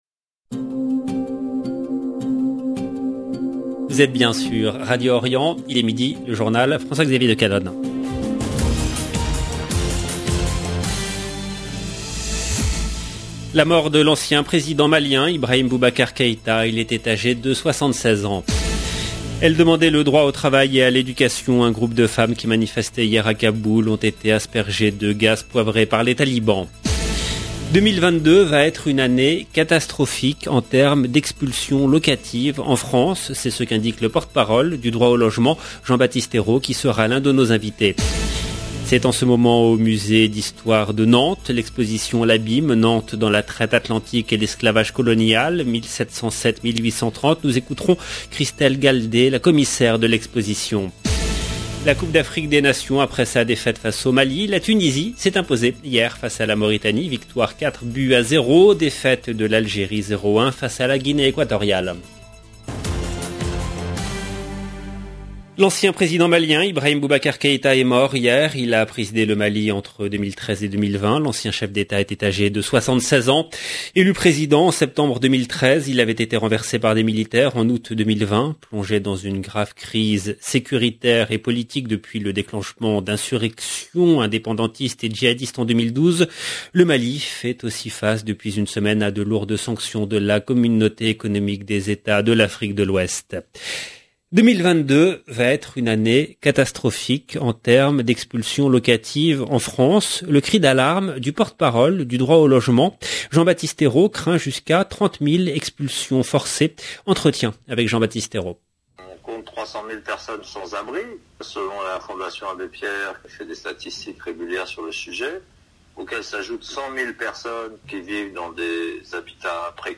LE JOURNAL DE MIDI EN LANGUE FRANCAISE DU 17/01/22